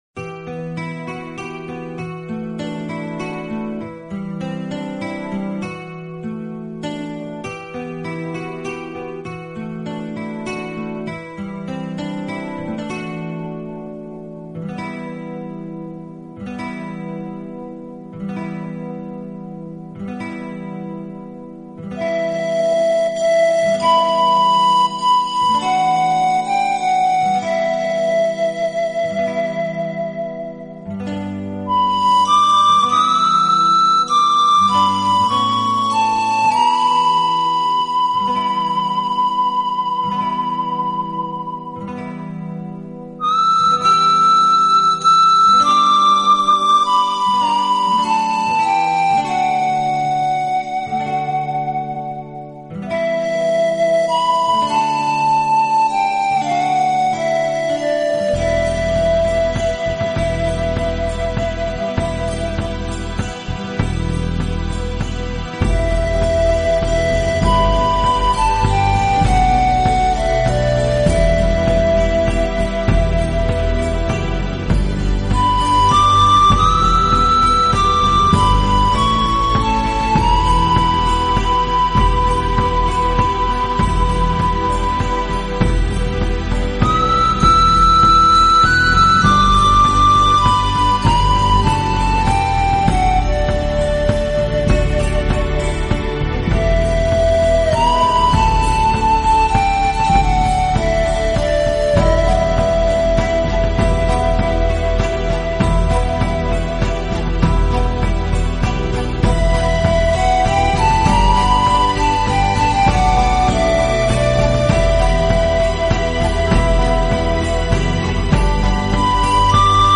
【排箫】
自然的乐器，充满了大自然奔放、和谐的意境，又具有一种太空般虚幻、飘渺的音色。